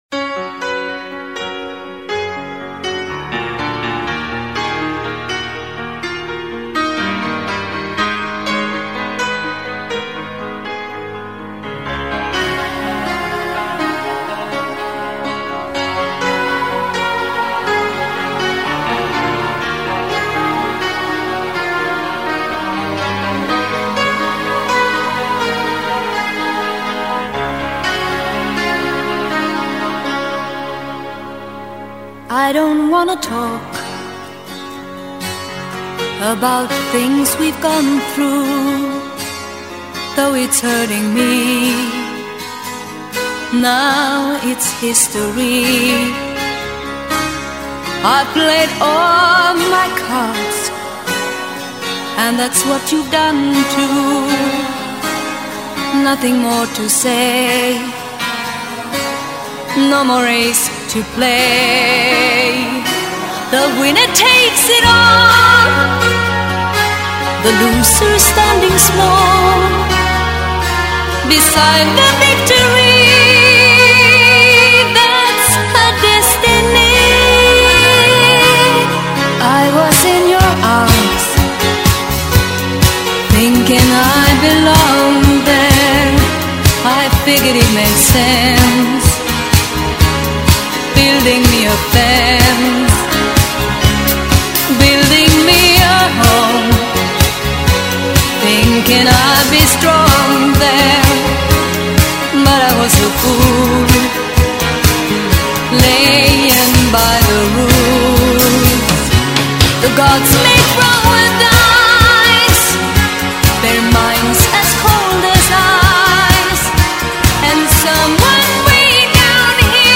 percussionist